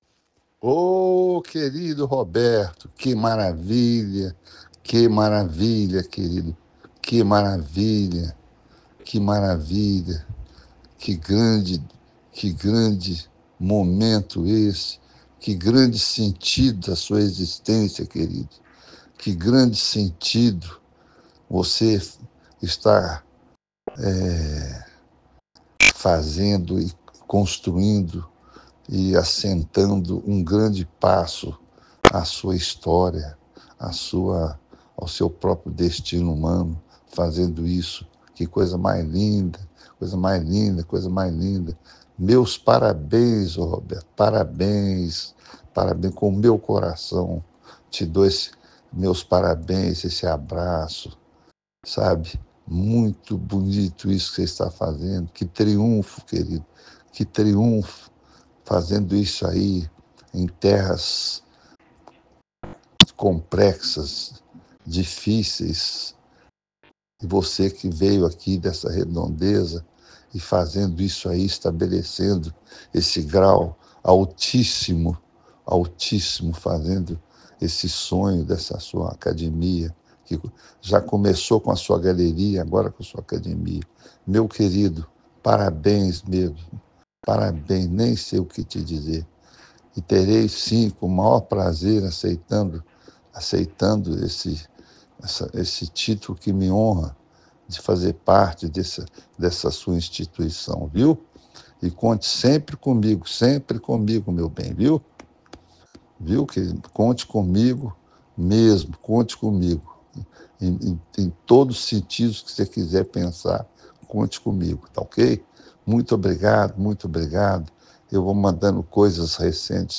Mensagem-de-Carlos-Bracher-por-ocasiao-do-convite-para-se-tornar-membro-da-Parcus-Academy.ogg